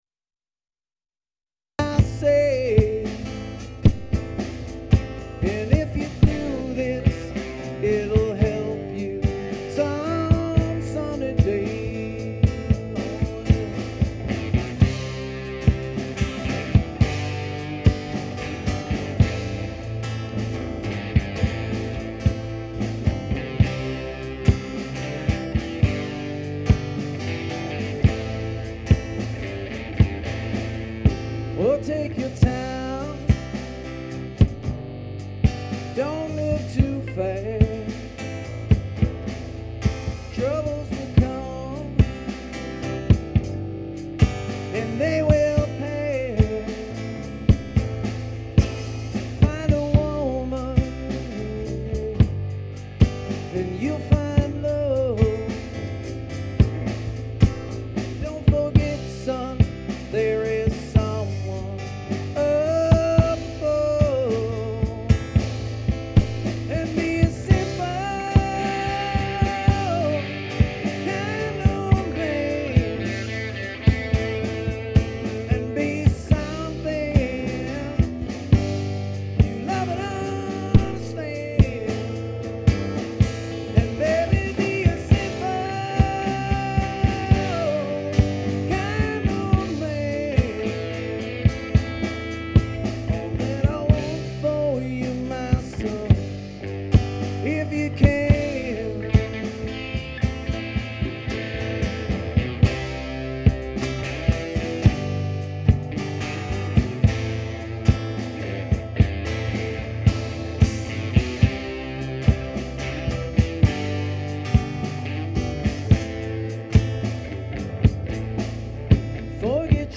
classic rock
female singer